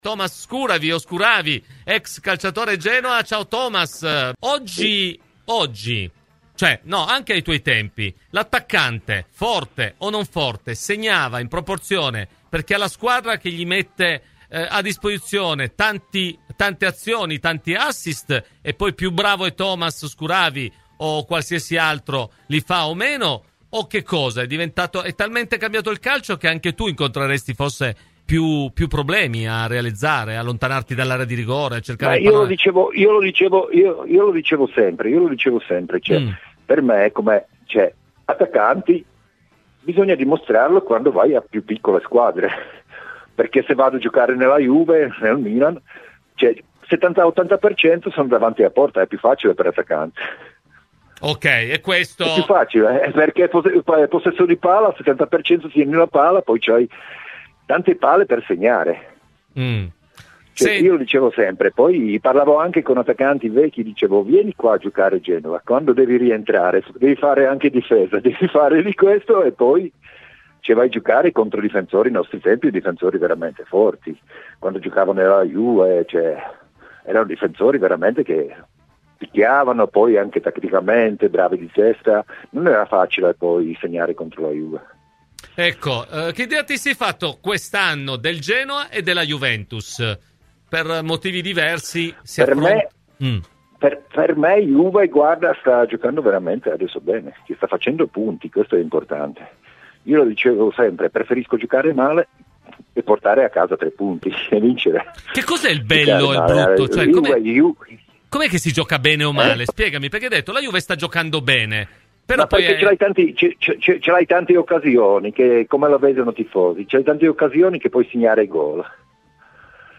Ospite oggi di “Cose di Calcio” su Radio Bianconera, è intervenuto Tomáš Skuhravý, grande attaccante del Genoa dal 1990 al 1995, che con la maglia rossoblù ha messo a segno 58 gol: “Oggi credo che sia più facile segnare per gli attaccanti – spiega, ricordando i suoi tempi – prima i difensori erano molto più tosti.